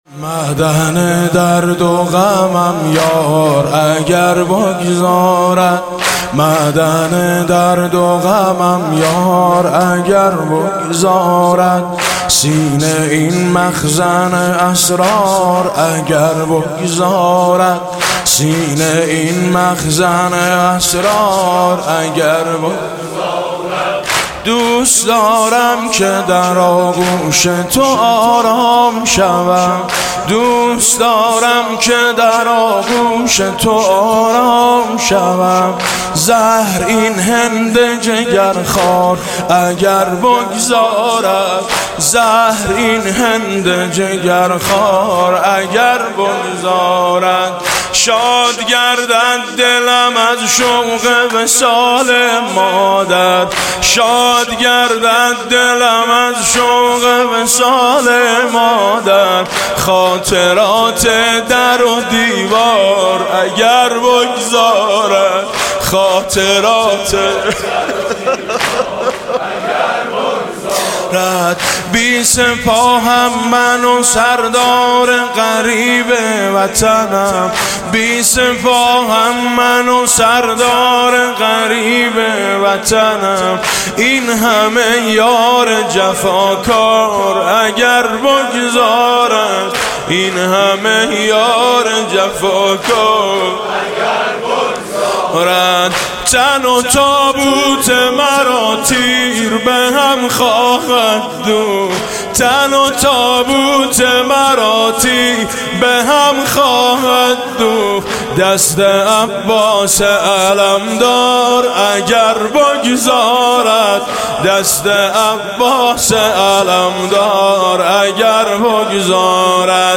«شهادت امام حسن 1393» زمینه: معدن درد و غمم